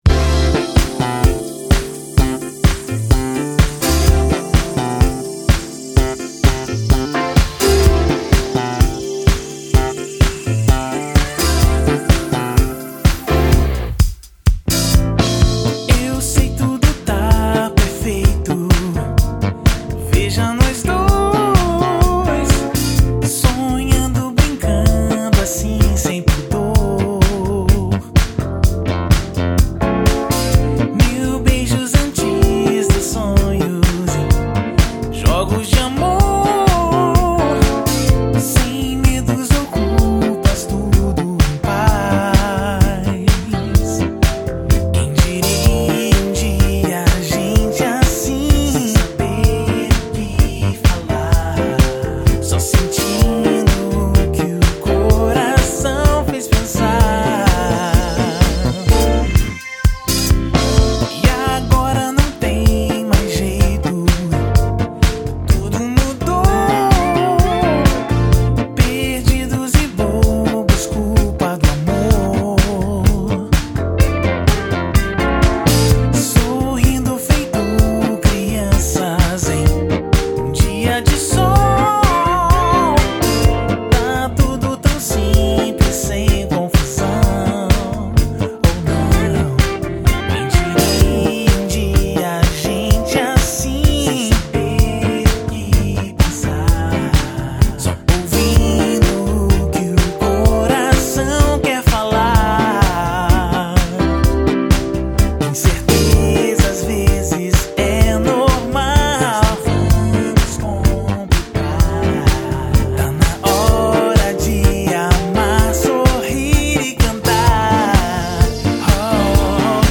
(Vocal)